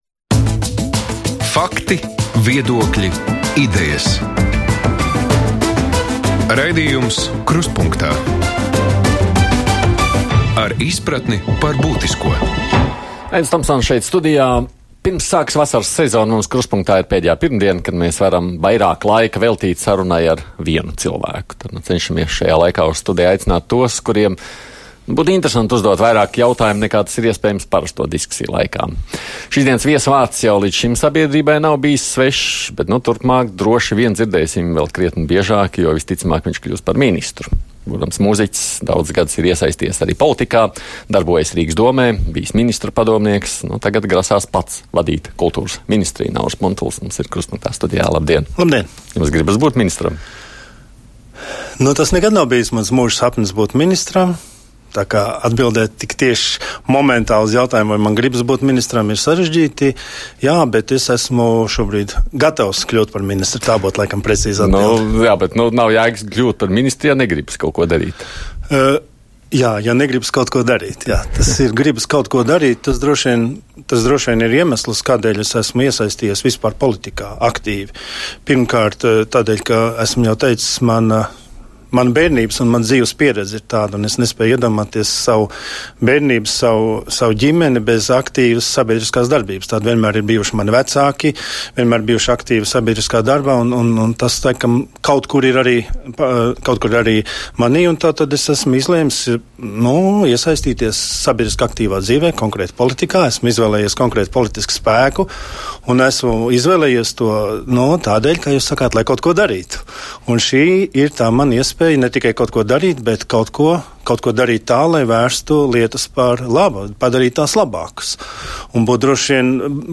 Saruna gan par kultūru, gan politiku. Mūsu viesis - mūziķis Rīgas domes deputāts, kurš varētu drīzumā kļūt par kultūras ministru, Nauris Puntulis.